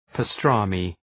{pə’strɑ:mı}